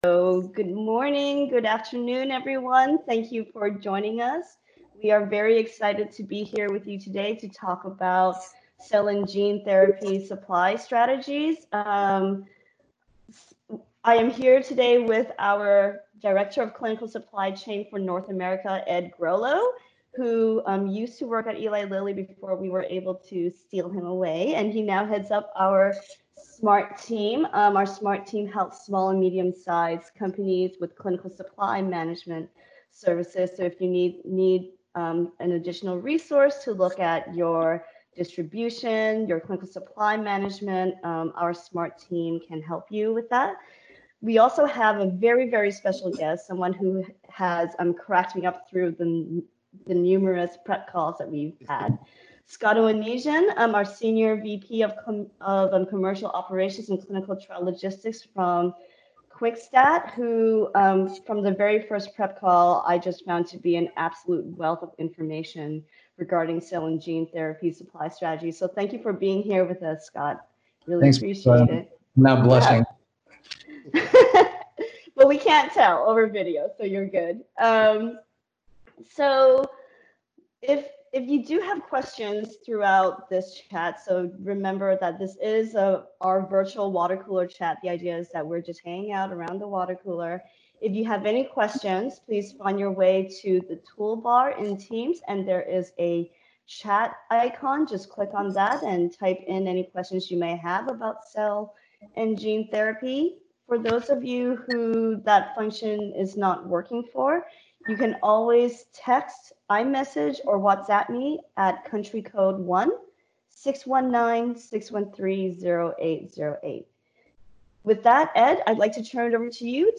We host a weekly virtual Water Cooler Chat series, where our subject matter experts host informal discussions around a number of clinical supply hot topics. In this edition, we are discussing Cell & Gene Therapy Supply Strategies